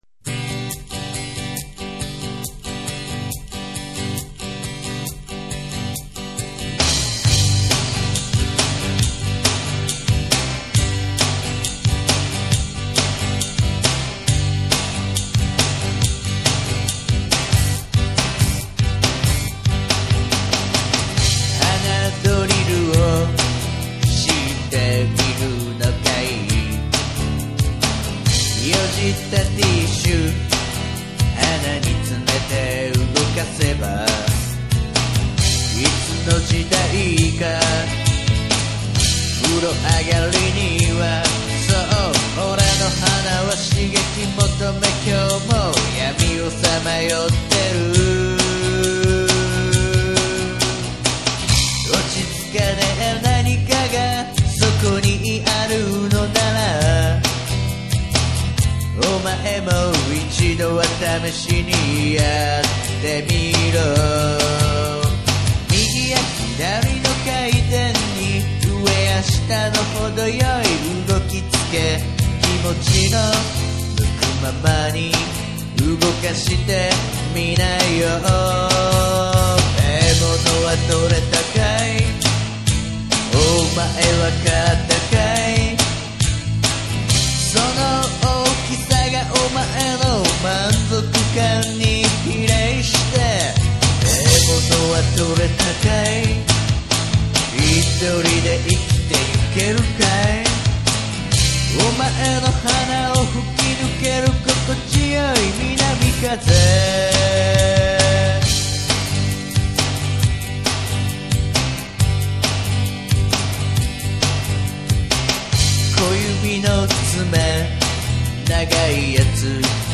作詞、作曲、実演、録音：奥様レコード
曲調的には、そうですね、ブルージーな感じをイメージしてみました。ブルースです。 せつないんすよ。
特に、サビの「獲物はとれたかい」の裏のギターがお気に入り。